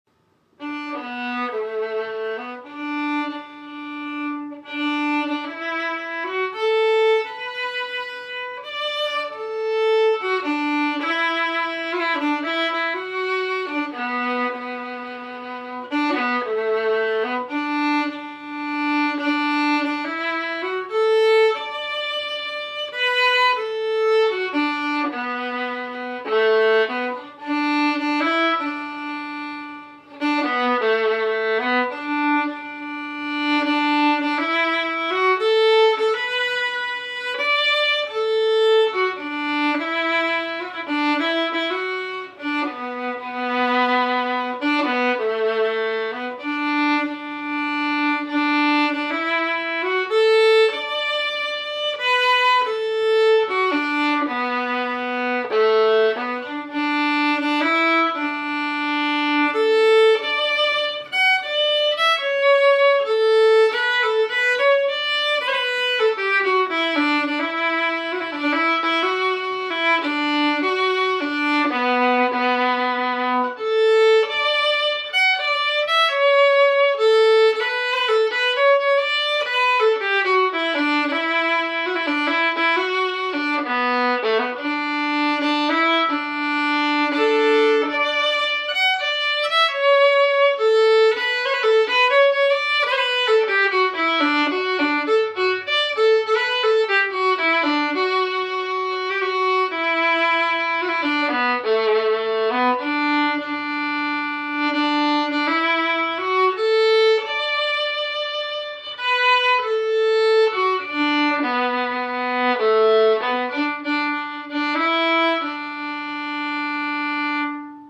Key: D
Form: Slow air (6/8)
Genre/Style: Scottish slow air or lament